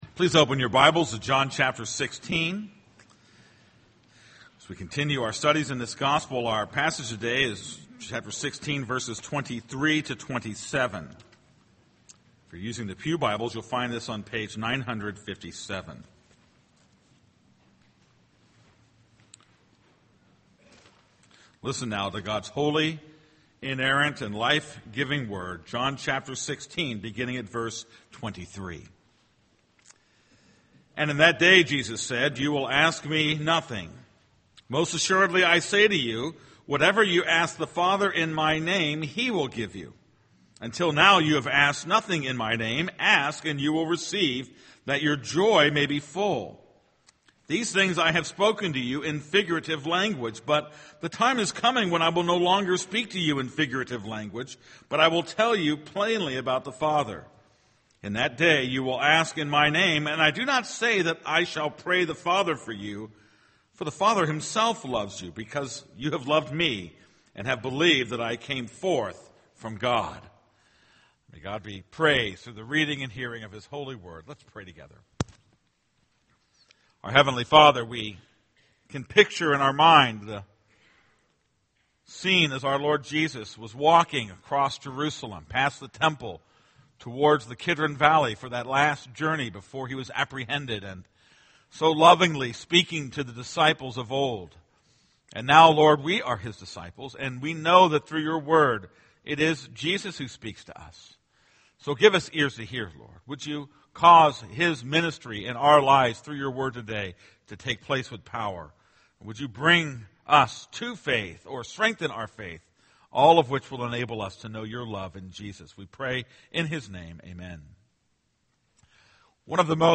This is a sermon on John 16:23-27.